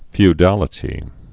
(fy-dălĭ-tē)